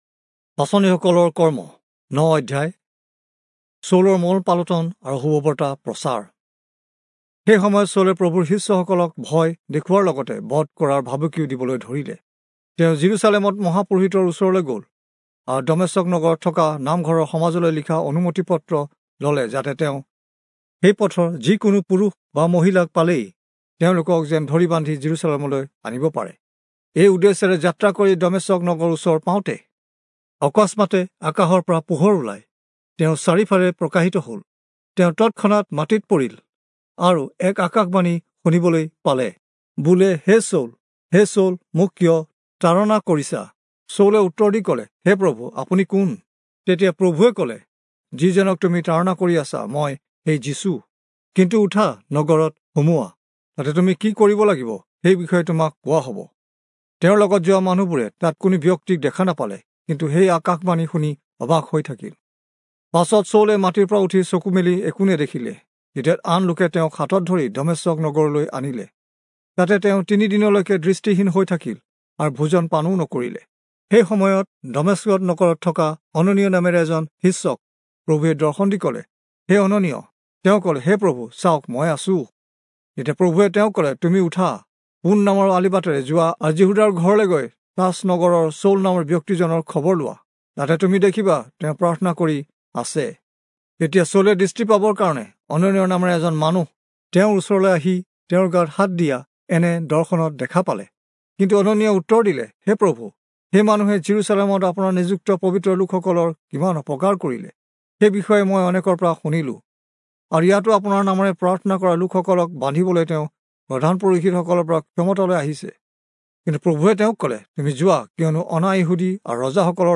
Assamese Audio Bible - Acts 23 in Mhb bible version